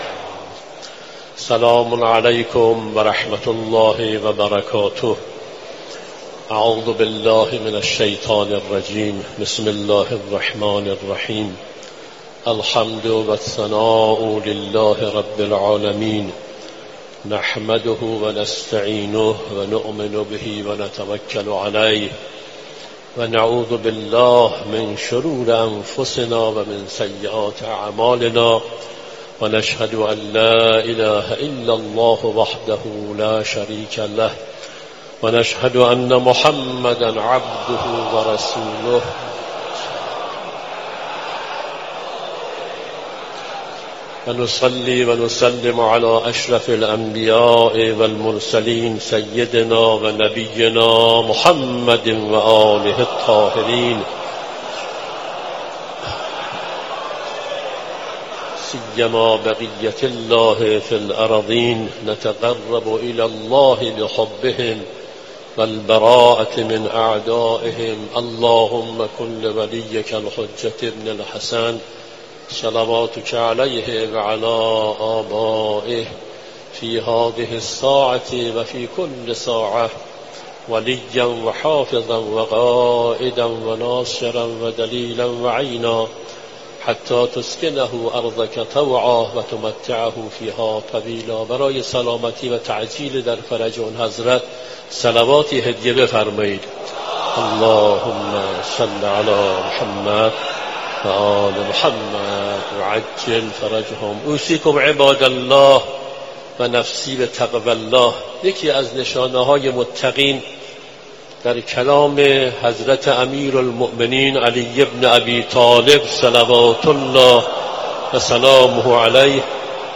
آری نماز جمعه … continue reading 21 tập # Islamic # Religion # Friday # Prayer # Tehran # Qom # خطبه # نماز # جمعه # تهران # Friday Fan # Sermons